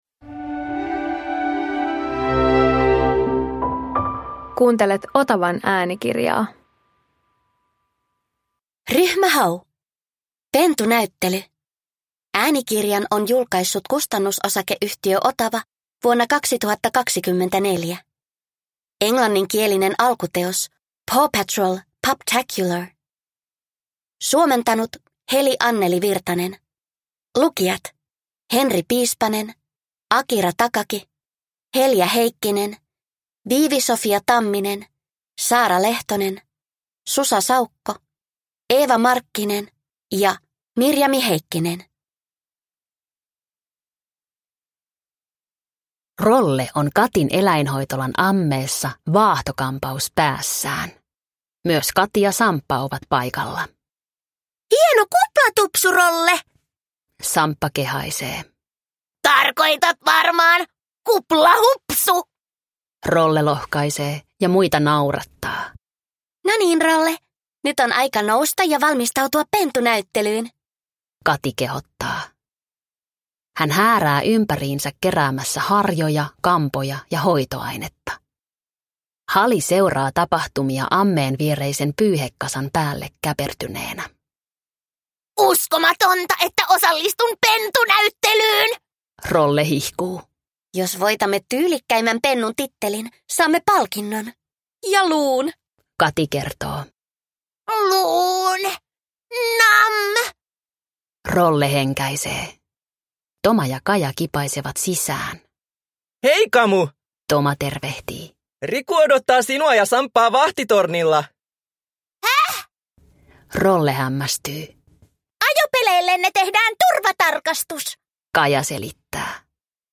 Ryhmä Hau - Pentunäyttely – Ljudbok